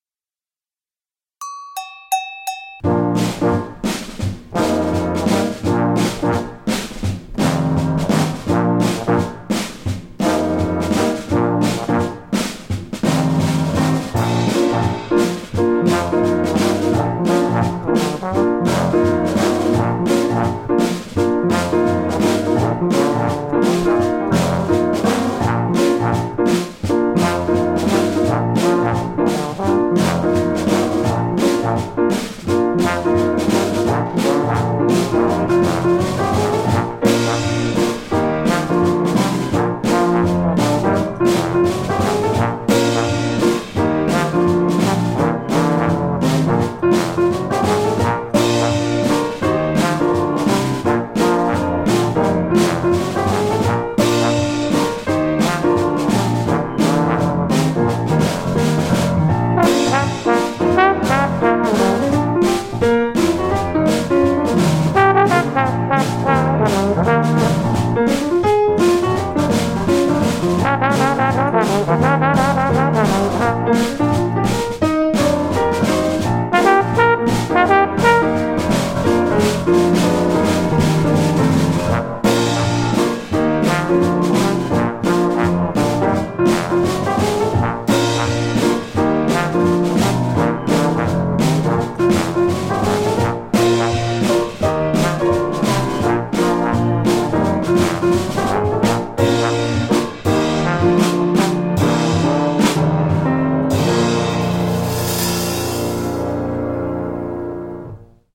4 beats of count-in 8 bars of intro
trade two-bar improvised solos
including a 4 bar ritard at the end
Practice playing the song along with the band!